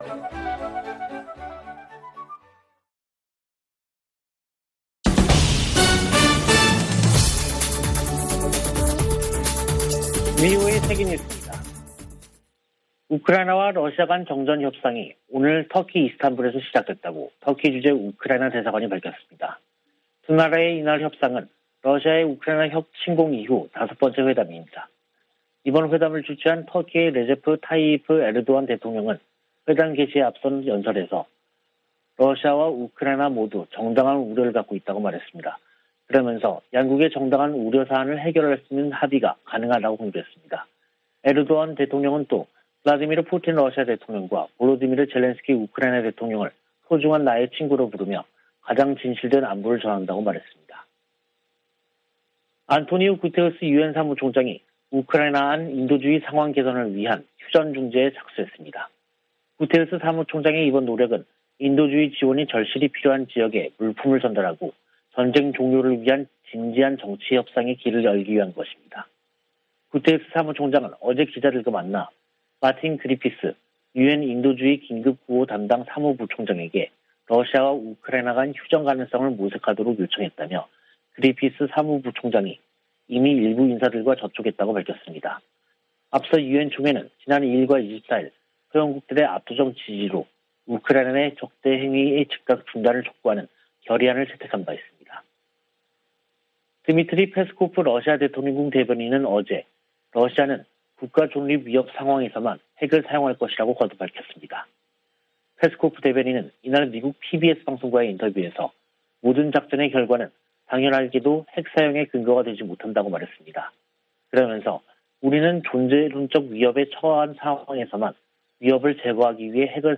VOA 한국어 간판 뉴스 프로그램 '뉴스 투데이', 2022년 3월 29일 3부 방송입니다. 한국 국방부는 북한이 지난 24일 발사한 ICBM이 '화성-17형'이 아닌 '화성-15형'인 것으로 판단했습니다. 한국 함동참모본부는 미한일 세 나라가 하와이에서 합참의장회의를 개최한다고 밝혔습니다. 미국이 새 유엔 안보리 대북제재 결의안 채택을 추진하고 있지만, 실현 가능성이 낮다는 전망이 지배적입니다.